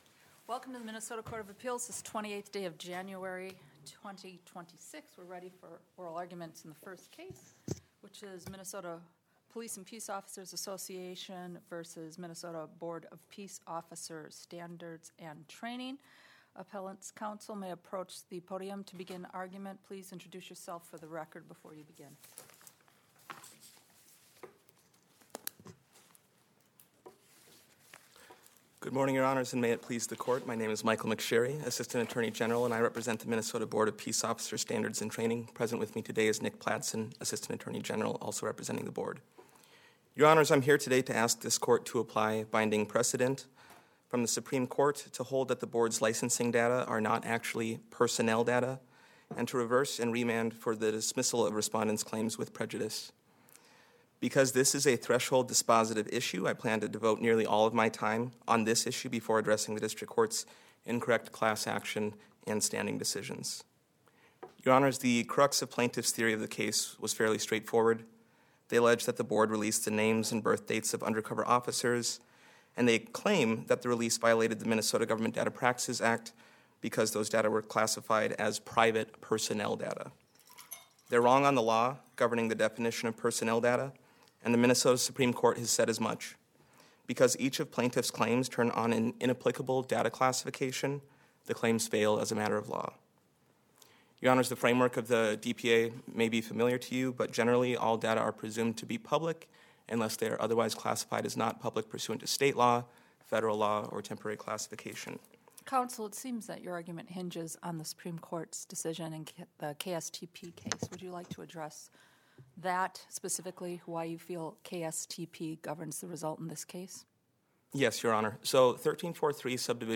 On Jan. 26, the court heard oral arguments in a case brought by the Minnesota Police and Peace Officers Association against the POST Board for releasing names and dates of birth of undercover police officers in 2024.
An audio recording of the oral argument can be found at the Court of Appeals website.